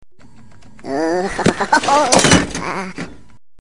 Minion Hohoho Sound Effect - MP3 Download
Minion-Hohoho-Sound-Effect-djlunatique.com_.mp3